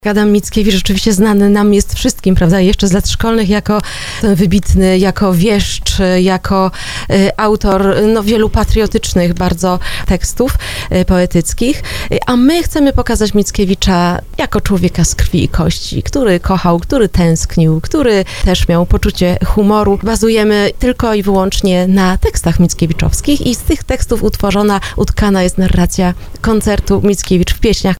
O koncercie mówiła na antenie Radia RDN Małopolska